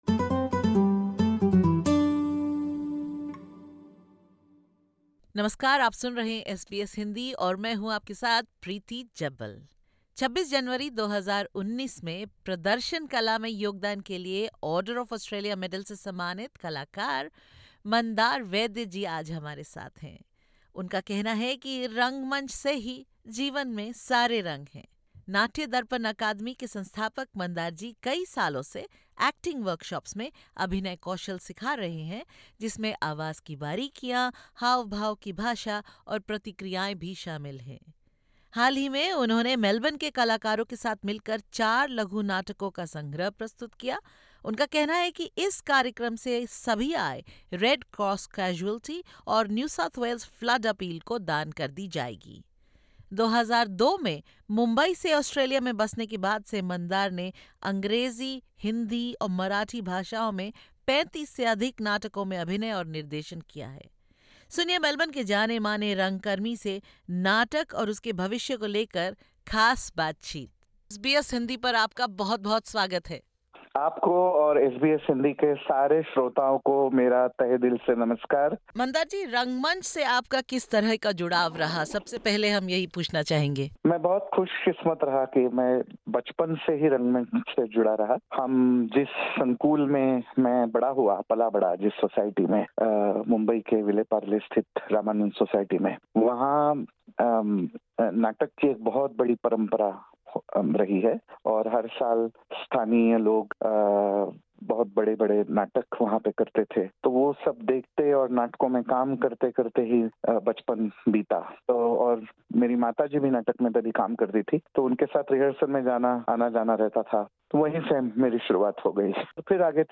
In this podcast, he talks to SBS Hindi about the emotions evoked by the plays' diverse themes, and how acting can enable a sense of 'fulfillment.'